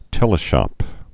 (tĕlĭ-shŏp)